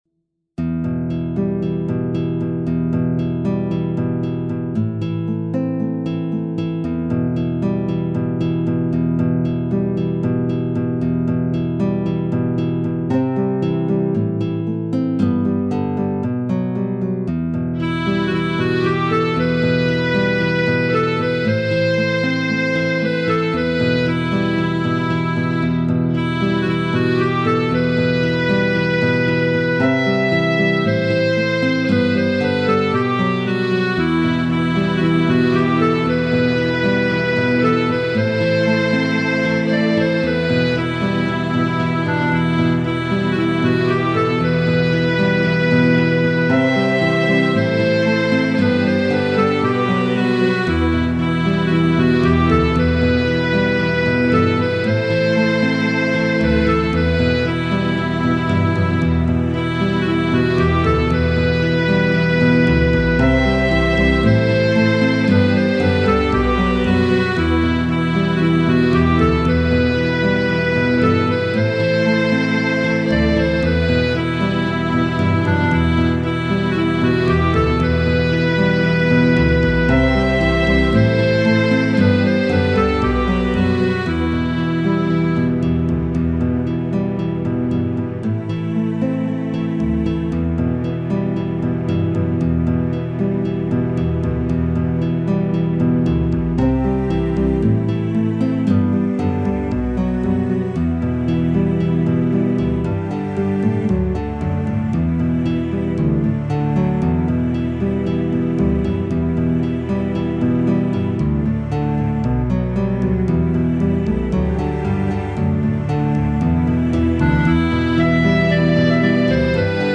Мистическая музыка Gothic Metal Кельтская музыка